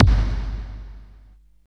30.02 KICK.wav